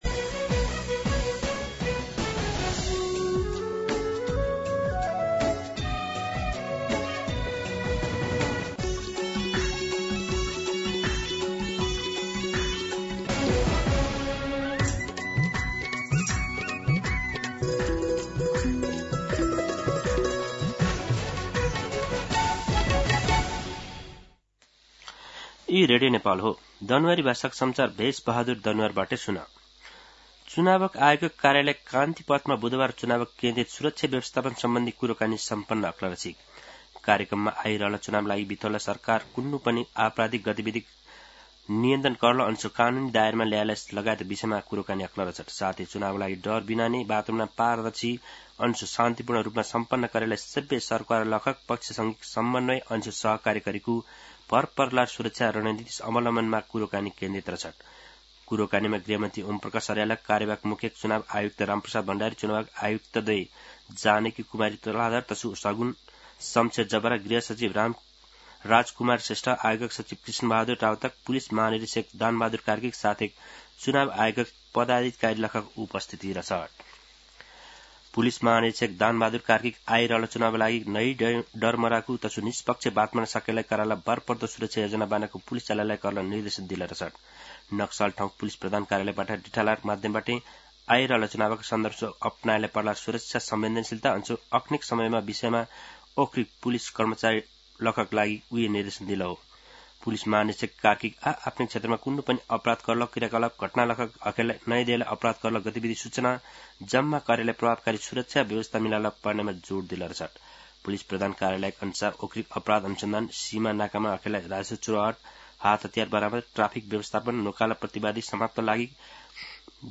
दनुवार भाषामा समाचार : ८ माघ , २०८२
Danuwar-News-10-8.mp3